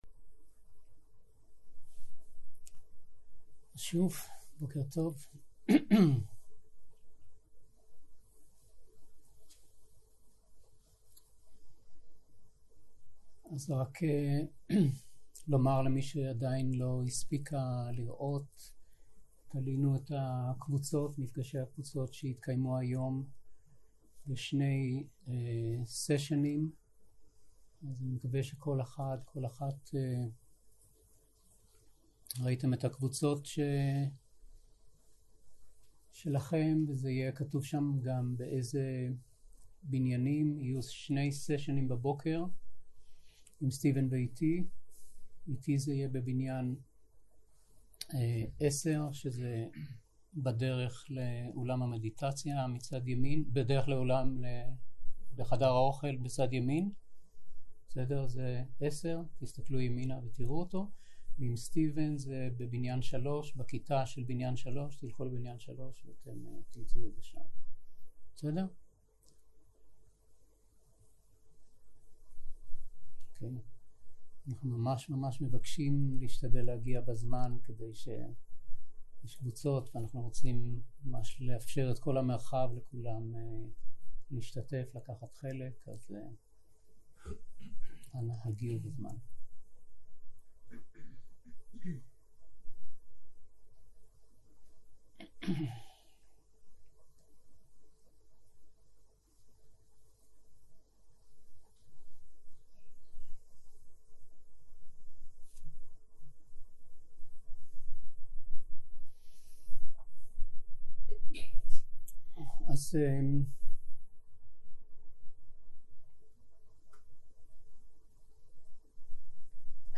יום 3 - בוקר - הנחיות למדיטציה - הקלטה 4
סוג ההקלטה: שיחת הנחיות למדיטציה